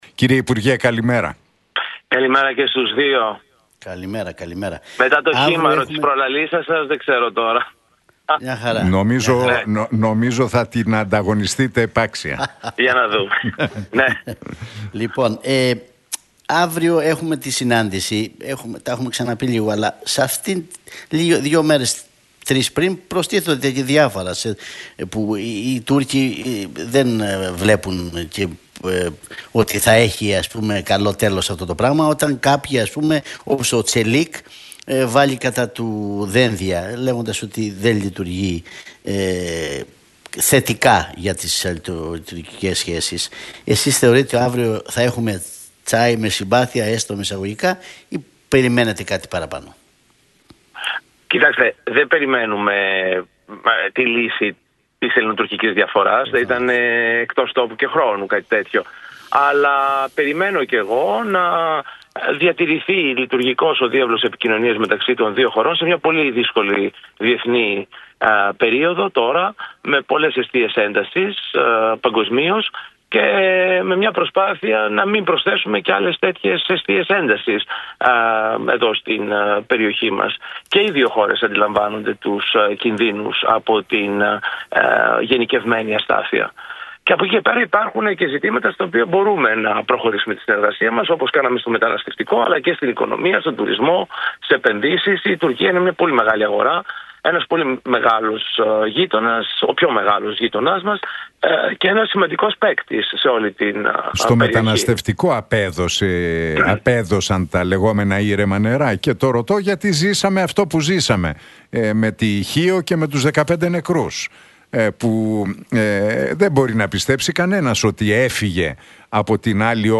ο κοινοβουλευτικός εκπρόσωπος της ΝΔ Δημήτρης Καιρίδης, μιλώντας στην εκπομπή του Νίκου Χατζηνικολάου